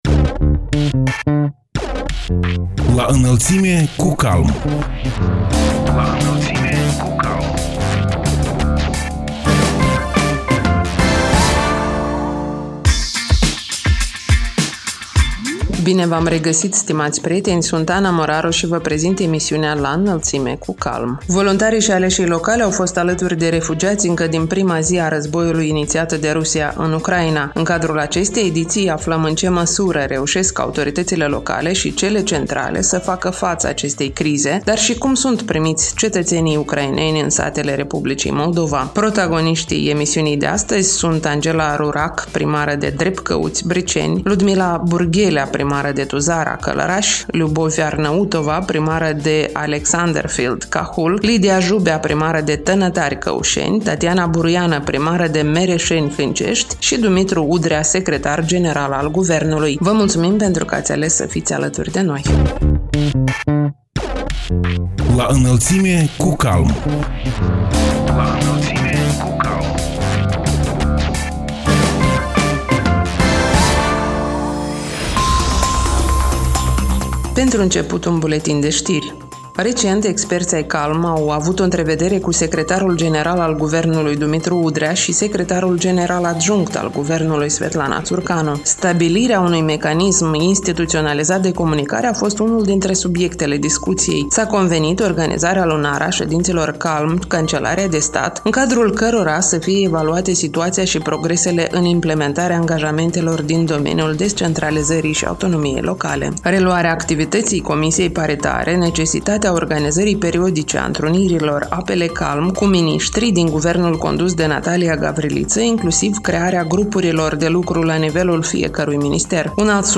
Voluntarii și aleșii locali au fost alături de refugiați încă din prima zi a războiului inițiat de Rusia în Ucraina. În cadrul emisiunii „La Înălțime cu CALM” aflăm în ce măsură reușesc autoritățile locale și cele centrale să facă facă față acestei crize, dar și cum sunt primiți cetățenii ucraineni în satele Republicii Moldova. Protagoniștii ediției sunt Angela Rurac, primară de Drepcăuți, Briceni; Ludmila Burghelea, primară de Tuzara, Călărași; Liubovi Arnăutova, primară de Alexanderfeld, Cahul; Lidia Jubea, primară de Tănătari, Căușeni; Tatiana Buruiană, primară de Mereșeni, Hâncești și Dumitru Udrea, secretar general al Guvernului.